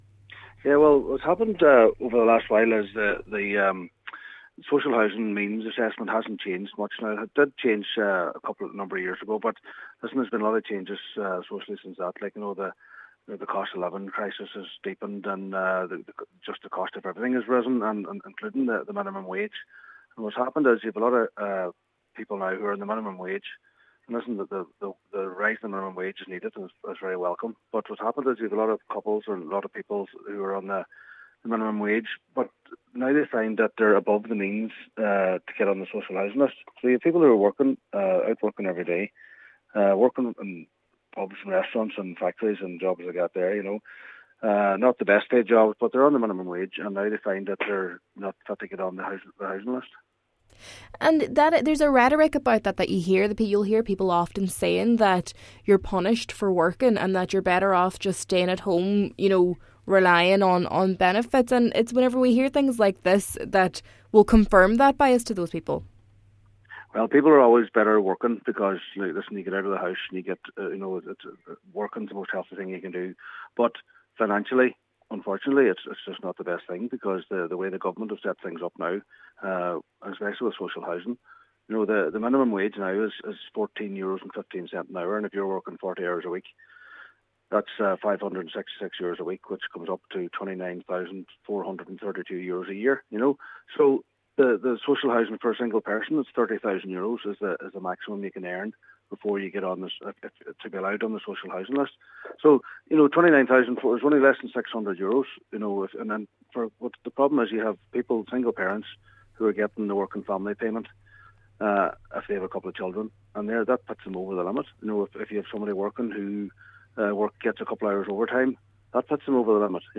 Cllr Brogan adds that current rules do not reflect rising living costs, increasing rents, and recent increases in the minimum wage: